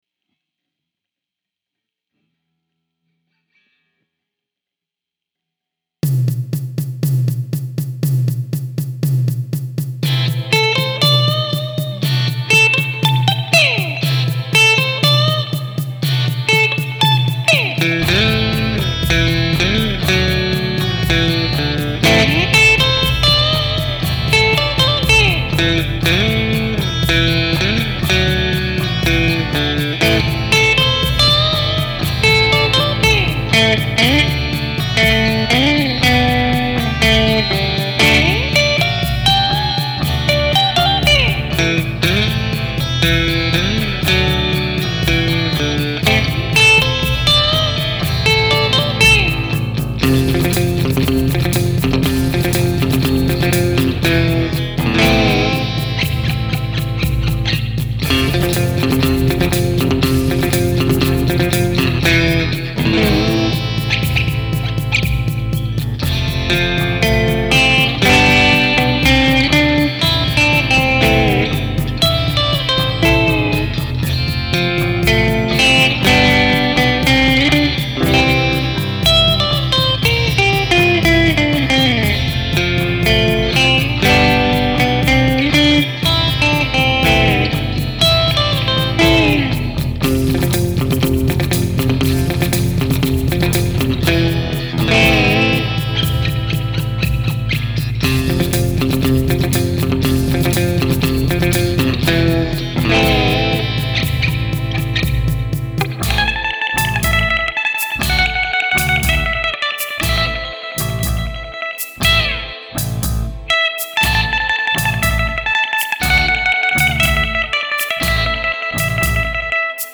New Jazzmaster
I switched pickups between middle and bridge which are the two positions I use most
That bridge pickup really cuts through